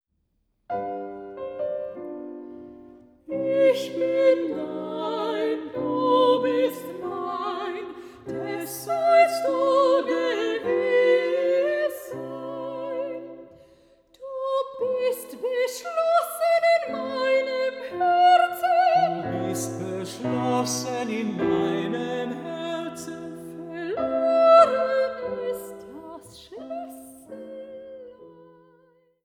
Mezzosopran
Tenor
Klavier